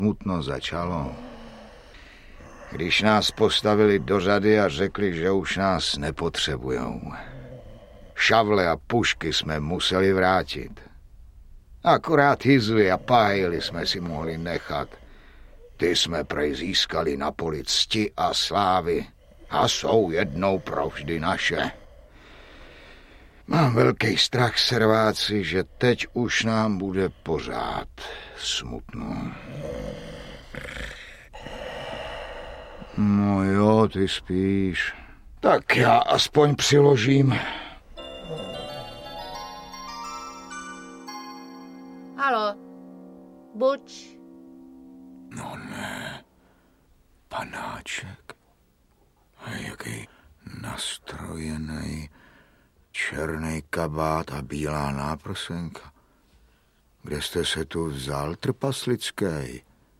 Audiobook
Read: Jitka Molavcová